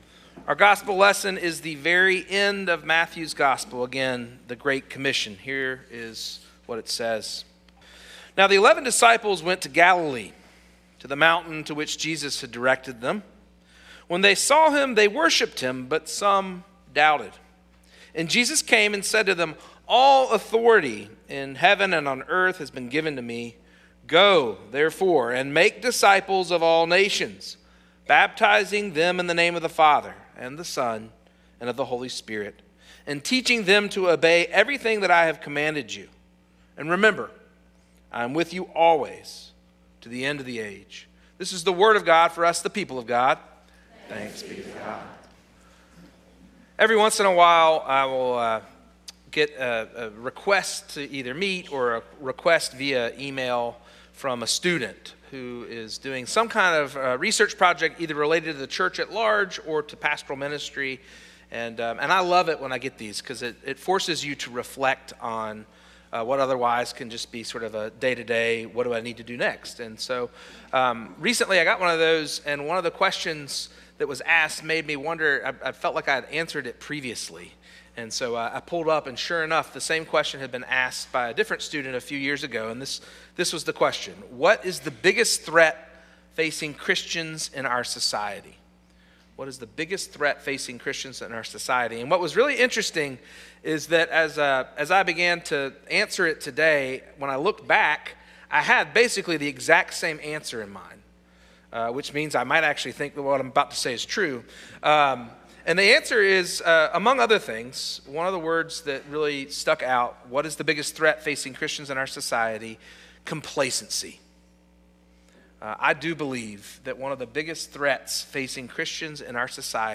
Sermon Series, Week 1